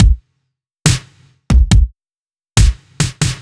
Index of /musicradar/french-house-chillout-samples/140bpm/Beats
FHC_BeatA_140-02_KickSnare.wav